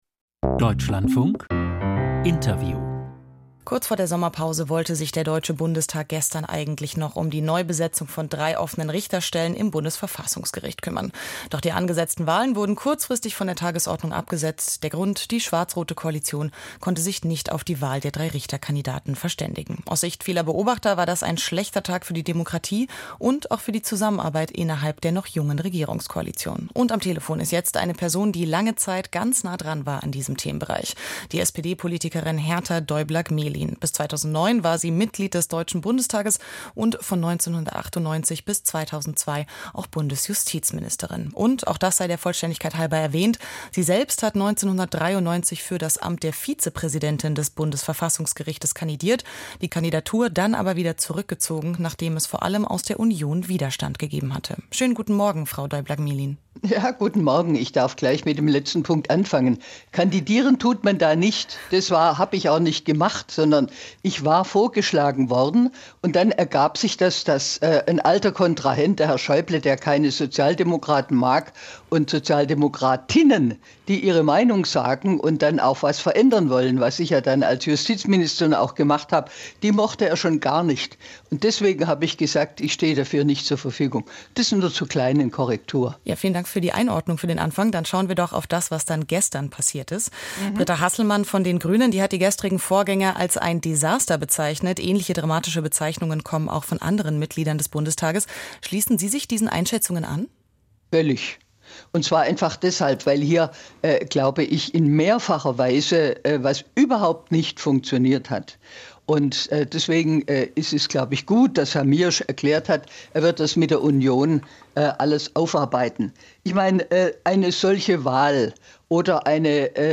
Verfassungsrichterwahl verschoben, Interview mit Herta Däubler-Gmelin, SPD